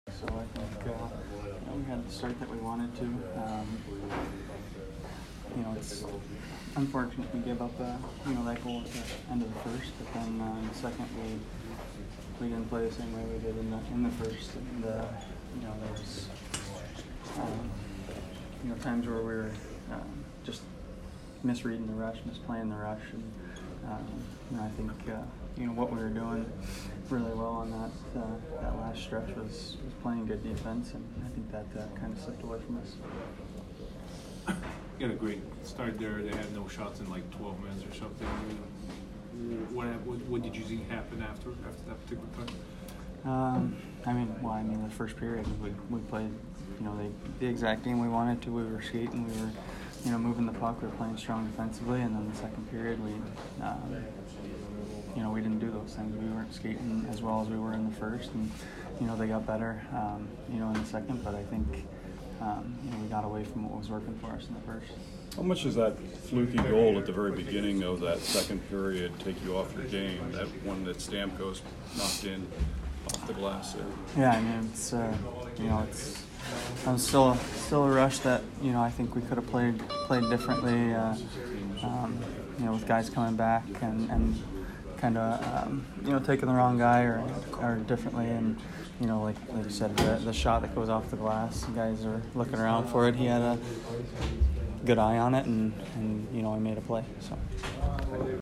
Petry post-game 12/28